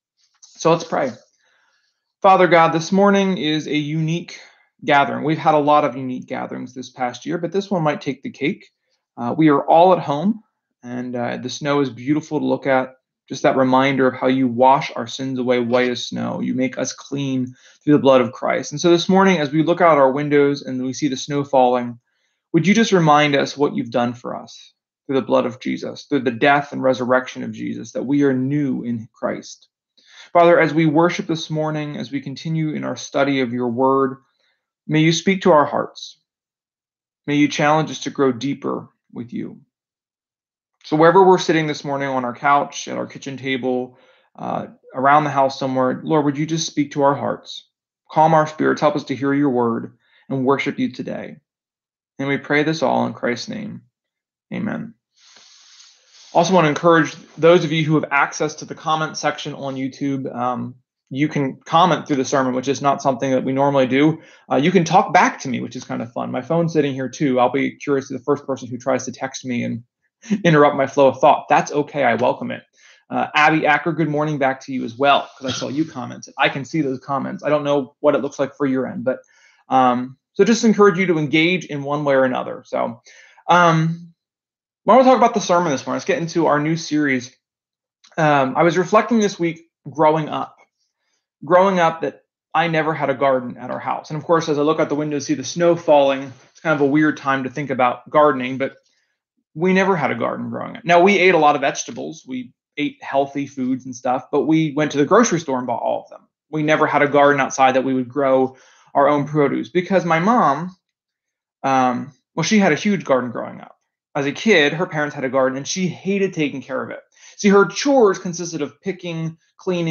Sermon-2.7.21.mp3